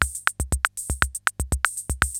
CR-68 LOOPS1 1.wav